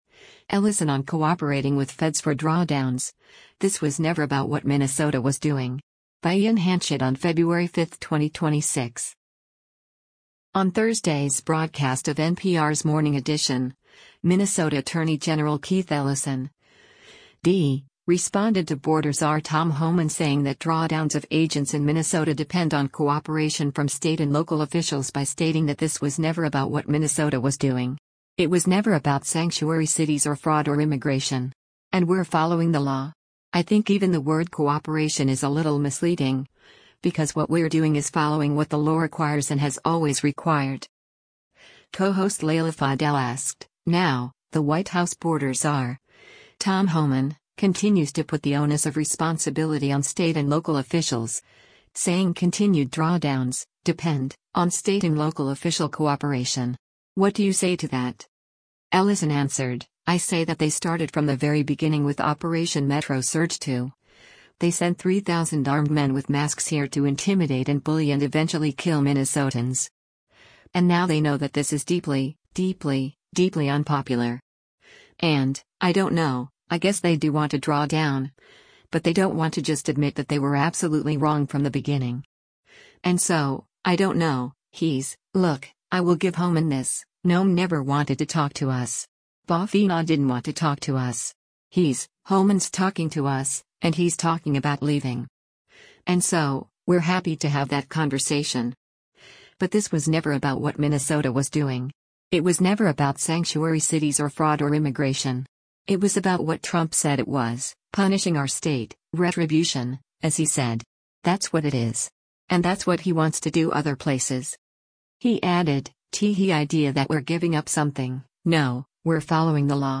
On Thursday’s broadcast of NPR’s “Morning Edition,” Minnesota Attorney General Keith Ellison (D) responded to Border Czar Tom Homan saying that drawdowns of agents in Minnesota depend on cooperation from state and local officials by stating that “this was never about what Minnesota was doing.